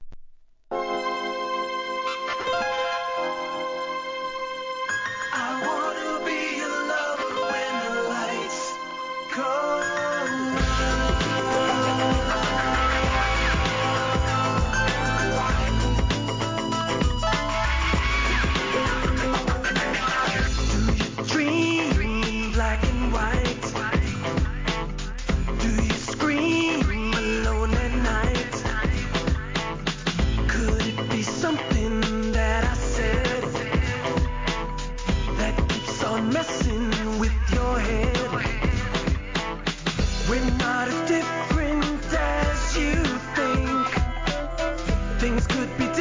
HIP HOP/R&B
白人シンガーによる2003年、PROMO ONLY!!